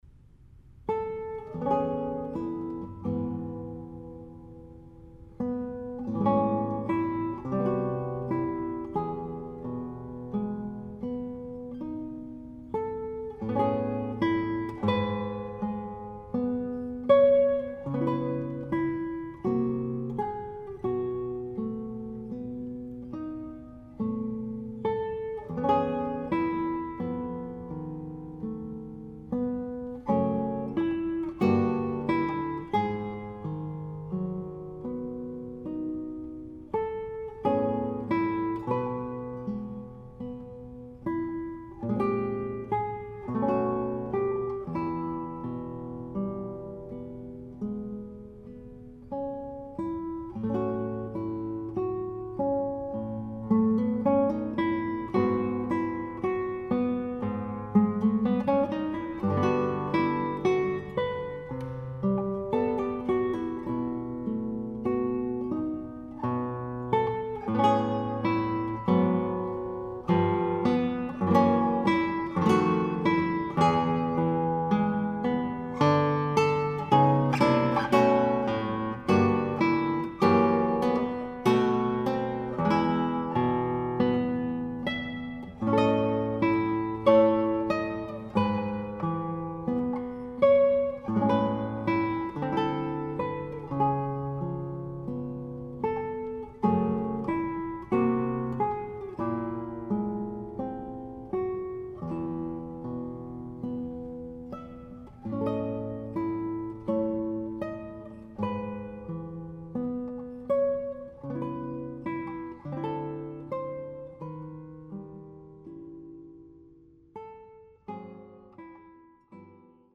Guitar
tongue-in-cheek musical parodies